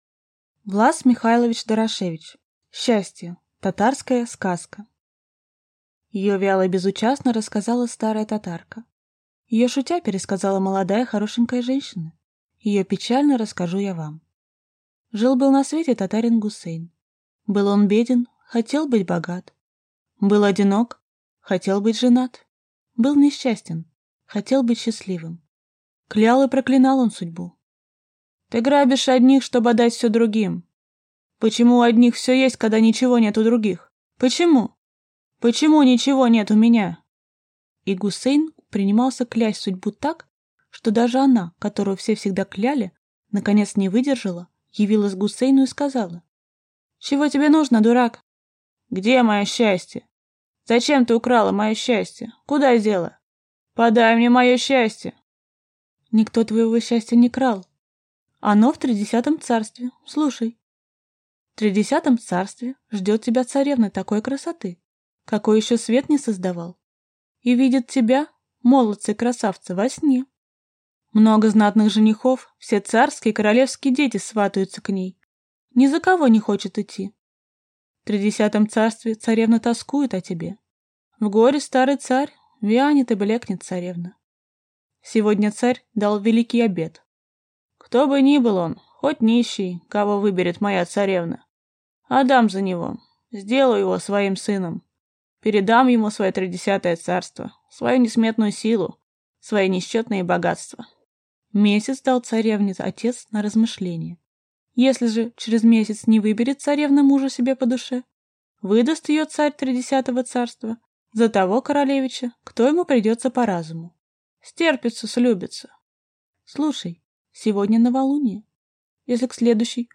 Аудиокнига Счастье | Библиотека аудиокниг